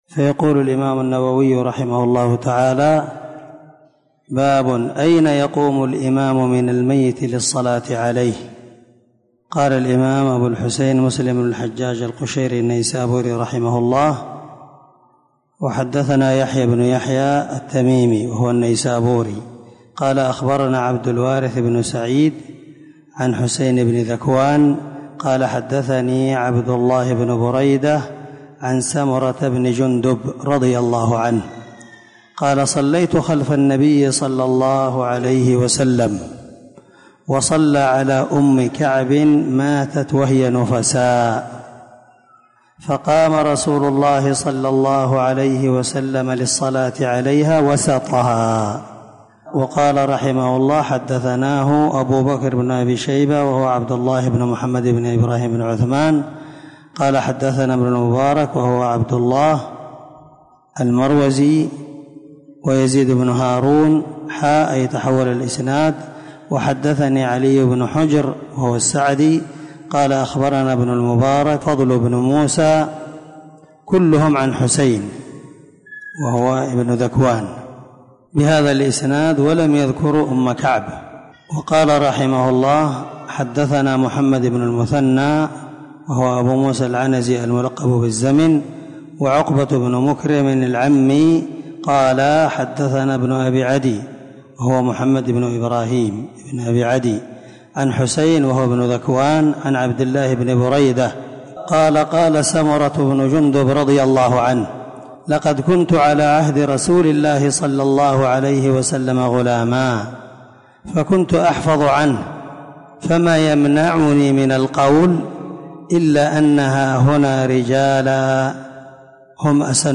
• سلسلة_الدروس_العلمية
• ✒ دار الحديث- المَحاوِلة- الصبيحة.